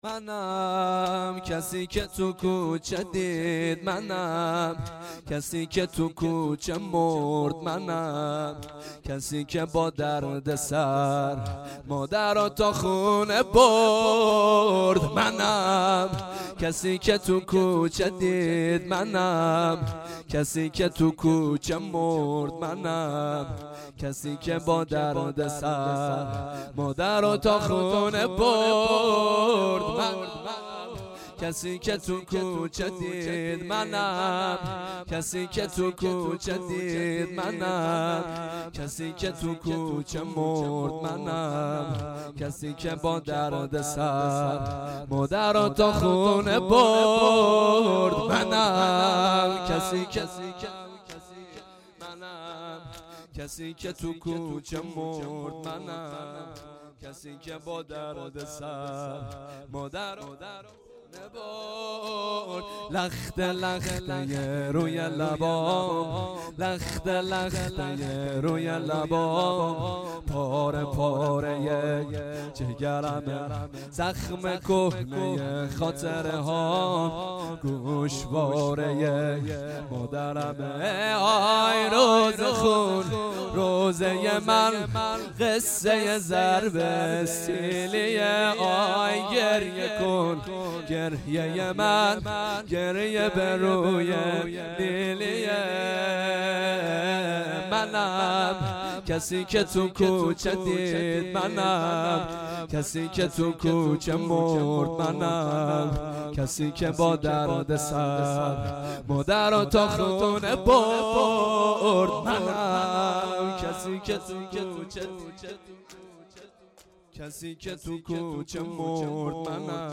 ویژه برنامه شهادت امام رضا(ع)